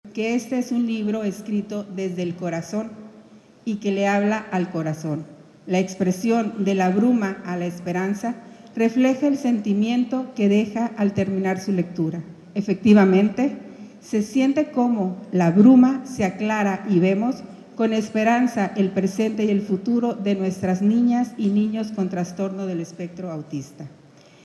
CITA-2-AUDIO-PRESIDENTA-DIF-SINALOA-PRESENTACION-DEL-LIBRO-AUTISMO-DE-LA-BRUMA-A-LA-ESPERANZA-.mp3